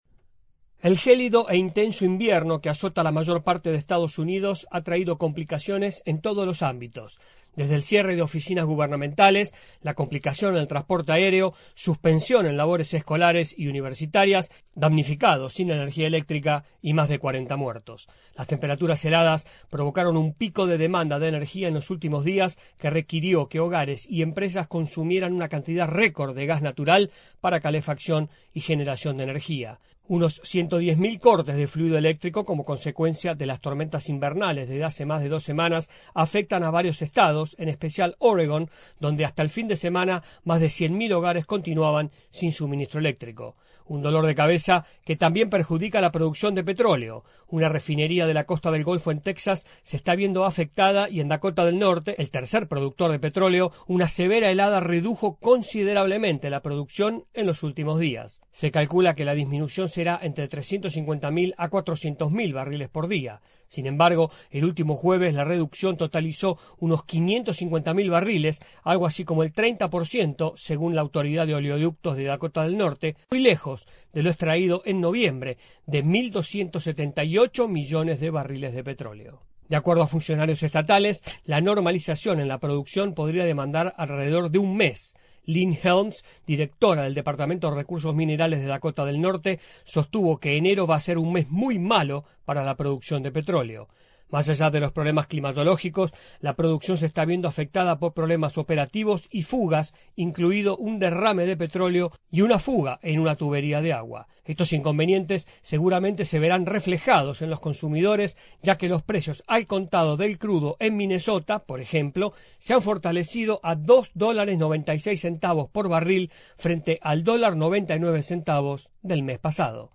El clima invernal en EEUU está provocando disrupciones en la producción petrolera y sigue afectando a miles de hogares por la falta de suministro eléctrico. Informa